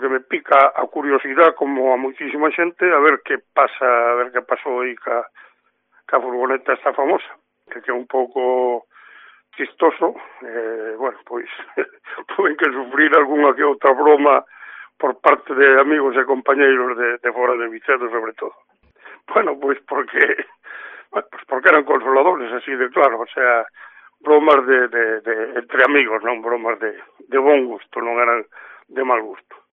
Declaraciones del alcalde Jesús Novo